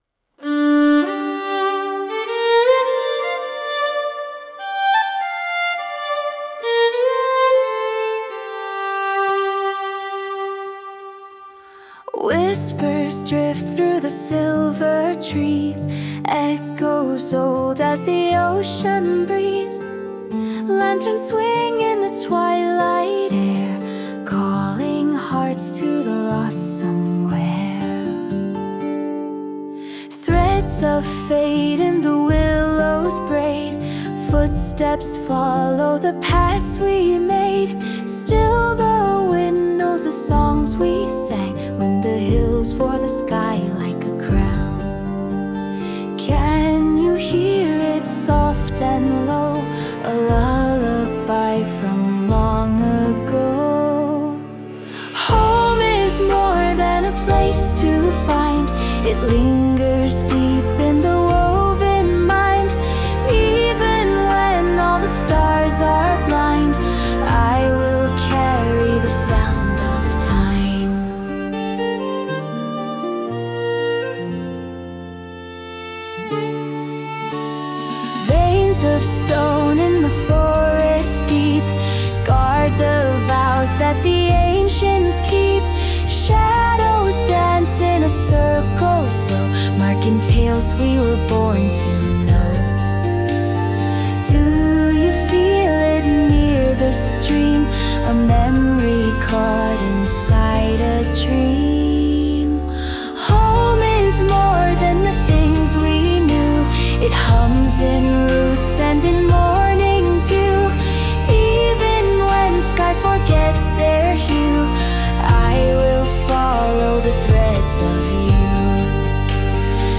女性ボーカル洋楽洋楽 女性ボーカルプロフィールムービーエンドロール作業BGMバラードゲーム静か切ないノスタルジック幻想的
著作権フリーオリジナルBGMです。
女性ボーカル（洋楽・英語）曲です。